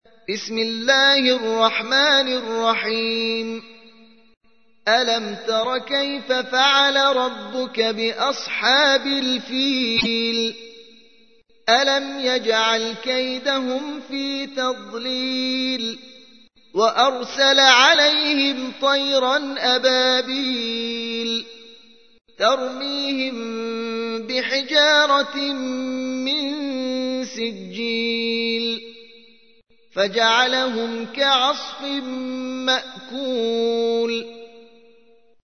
القرآن الكريم 105. سورة الفيل